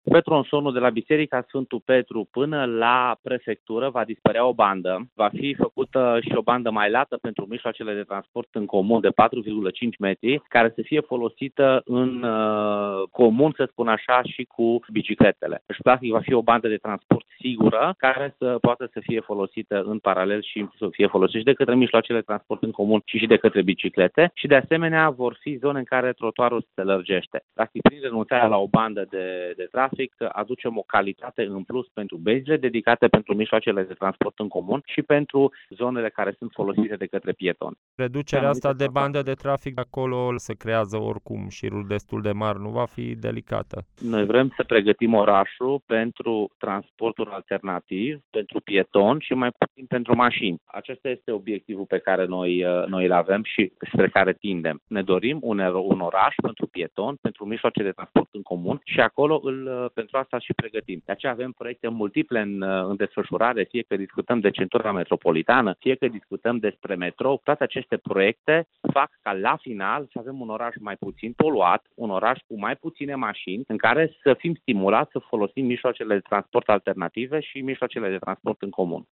Viceprimarul Dan Tarcea a oferit detalii pe această temă, în declarația de mai jos: